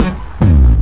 BOOMBASS  -L.wav